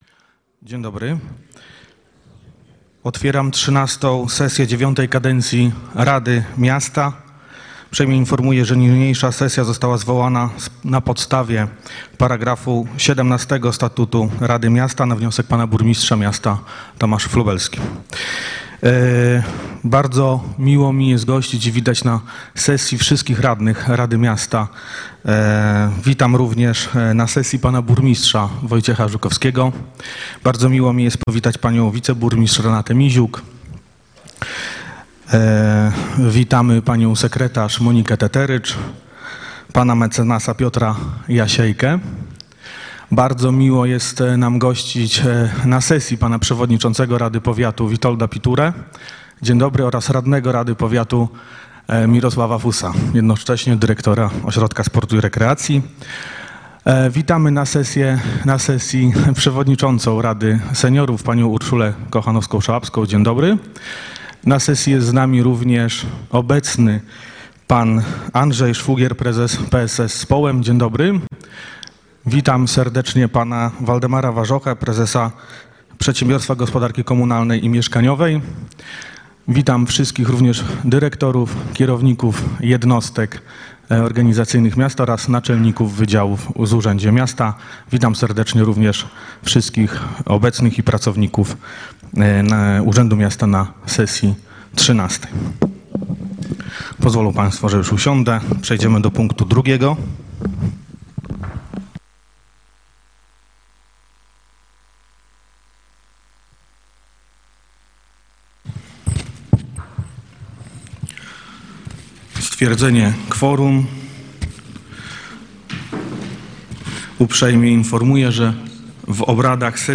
Rada Miasta Tomaszów Lubelski XIII Sesja w dniu 29 kwietnia 2025, godz. 08:00w sali konferencyjnej Urzędu Miasta, przy ul. Lwowskiej 57 w Tomaszowie Lubelskim.